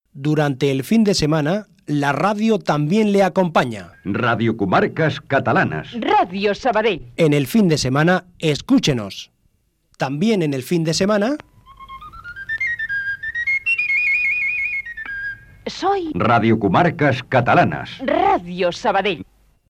Indicatiu del cap de setmana de la cadena i l'emissora